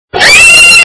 rabbit_injured2.mp3